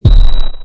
mnl-bdrum2.mp3